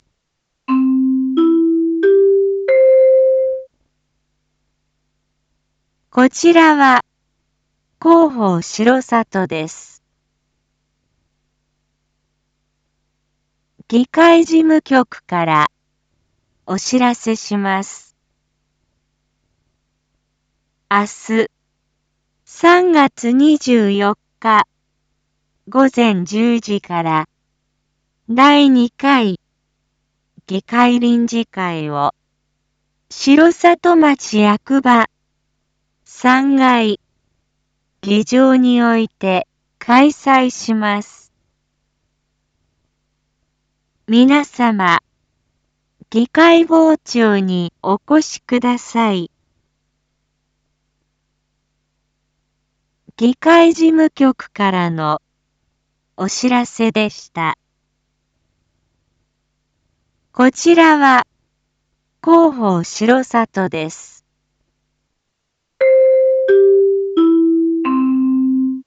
Back Home 一般放送情報 音声放送 再生 一般放送情報 登録日時：2026-03-23 19:01:15 タイトル：R8.3.24 第２回議会臨時会① インフォメーション：こちらは広報しろさとです。